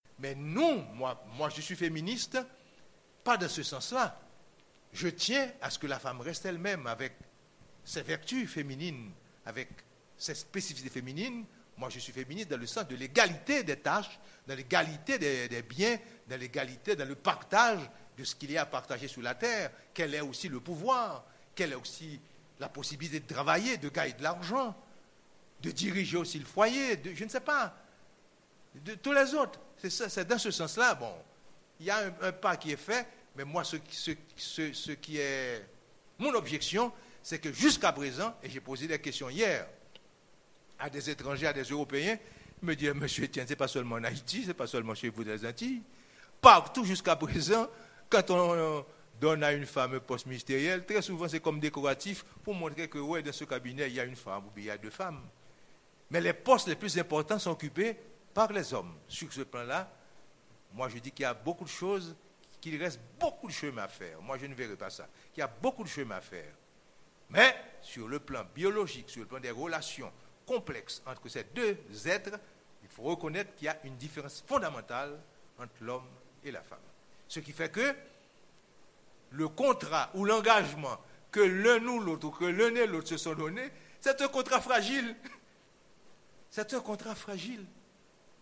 Conférence Caraïbe de Frankétienne le 11 avril 2003 à la Médiathèque Caraïbe, à l'occasion de la 14ème édition du Salon du Livre de Pointe-à-Pitre.